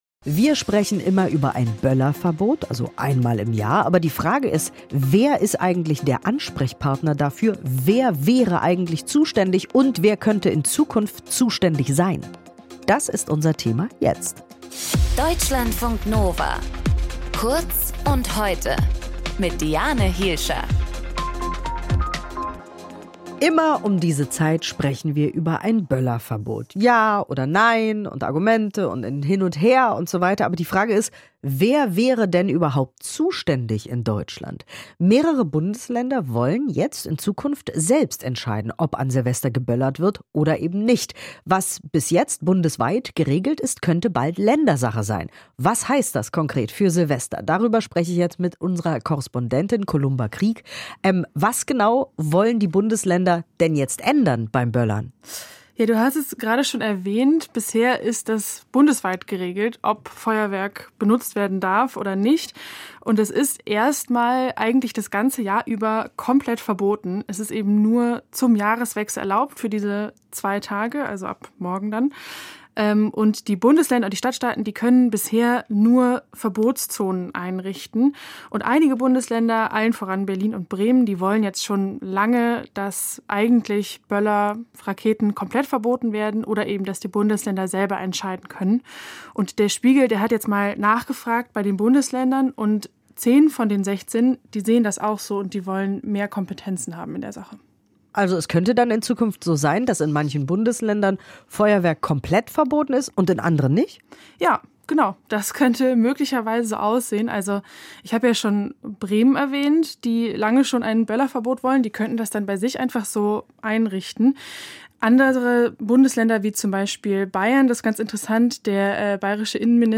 Moderation
Gesprächspartnerin